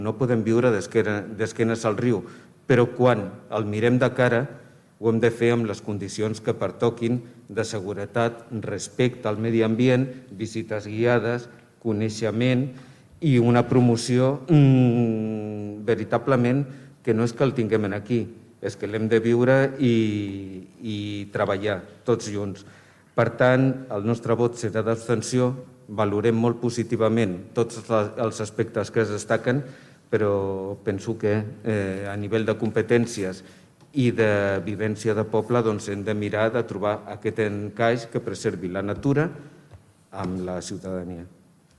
Aquest és un dels acords al qual s’ha arribat al ple de l’Ajuntament de Tordera.
Rafa Delgado presenta els motius de l’abstenció: